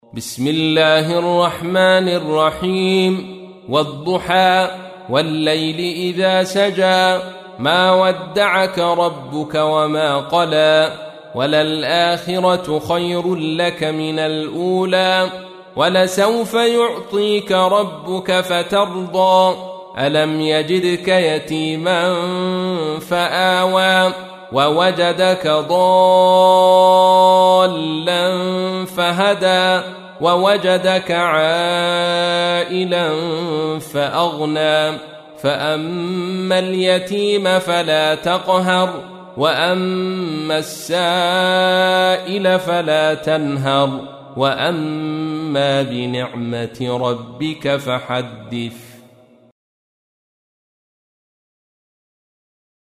تحميل : 93. سورة الضحى / القارئ عبد الرشيد صوفي / القرآن الكريم / موقع يا حسين